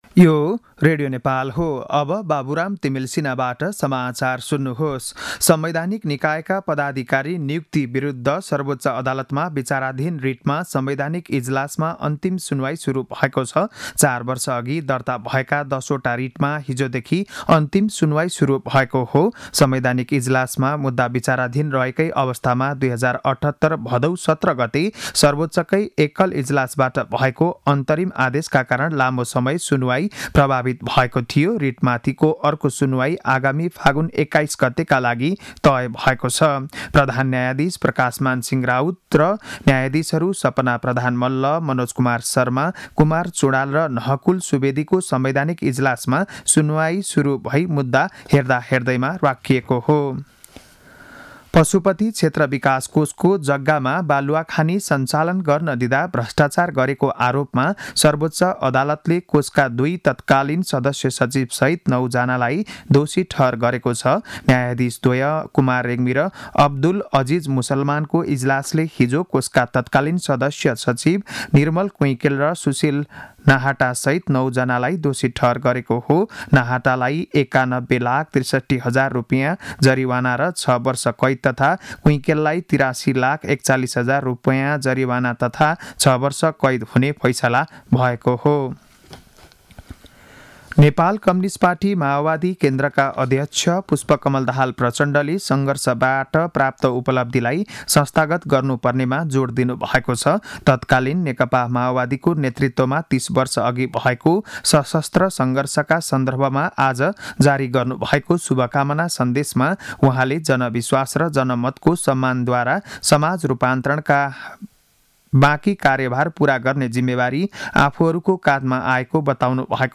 बिहान ११ बजेको नेपाली समाचार